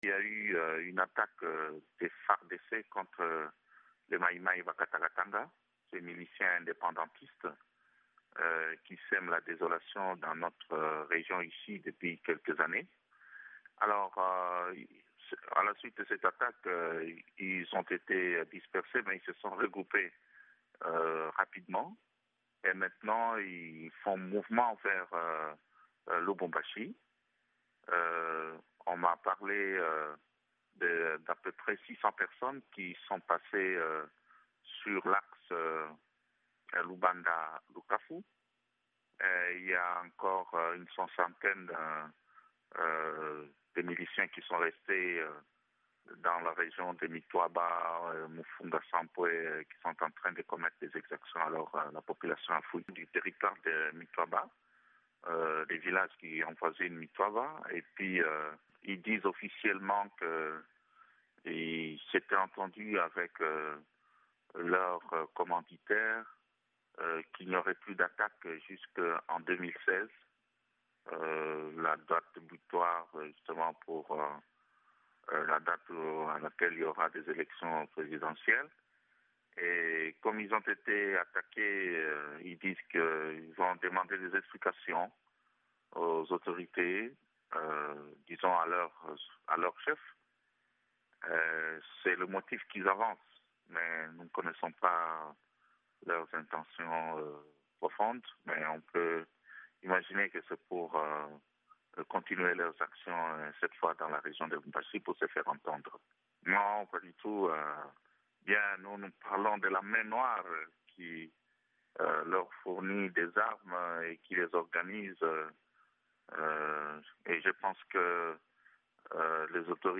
Chassé des zones montagneuses, le gros du groupe de sécessionnistes Bakata Katanga se dirige plutôt vers Lubumbashi, la capitale de la province riche en minerais, selon l’évêque du diocèse catholique de Kilwa-Kasenga, Monseigneur Fulgence Muteba, que VOA Afrique a joint par téléphone.